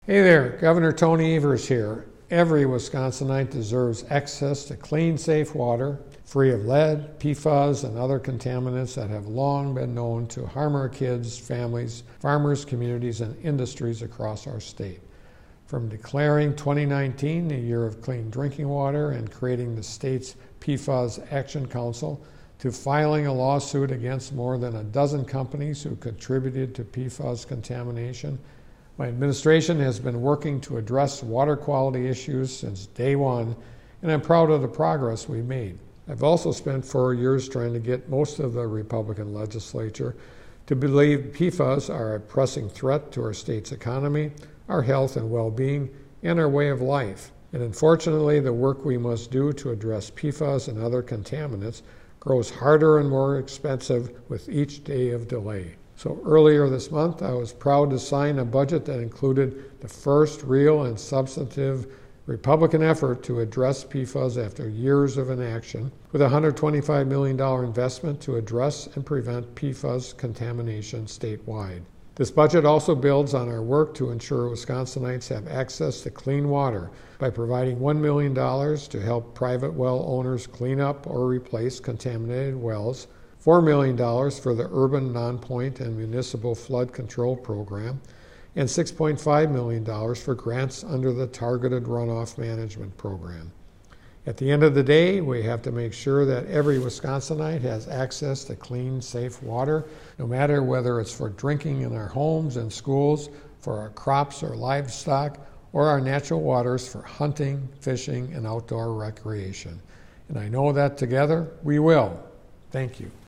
Weekly Dem radio address: Gov. Evers on historic budget investment to address PFAS statewide - WisPolitics
MADISON — Gov. Tony Evers today delivered the Democratic Radio Address on his historic budget investment to address PFAS contamination statewide. The 2023-25 biennial budget builds upon the governor’s work over the past four years to ensure Wisconsinites have access to clean water and includes one of the first meaningful investments by Republican legislators to address PFAS contamination statewide.